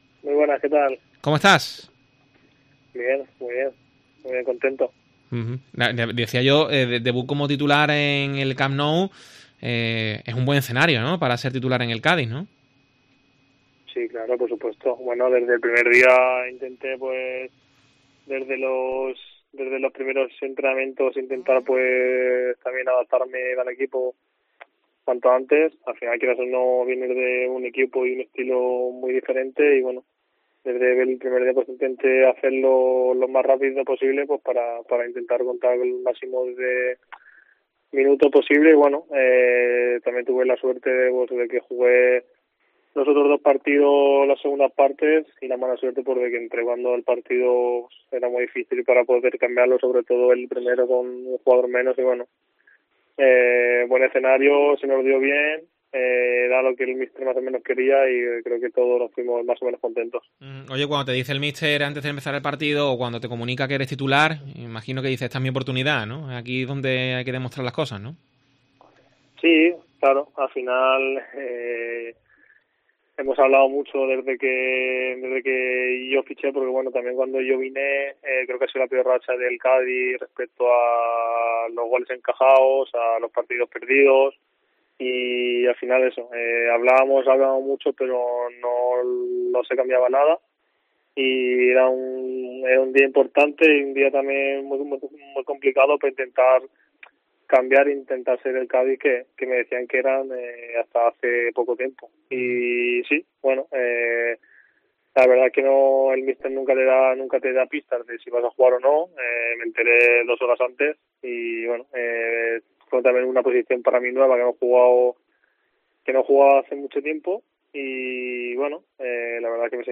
Rubén Sobrino analiza la actualidad del Cádiz en COPE